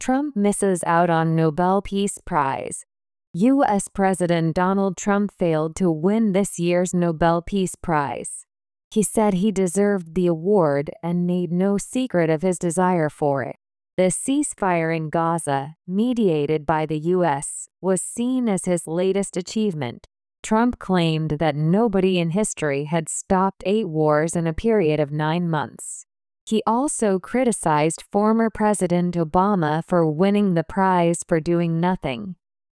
【スロースピード】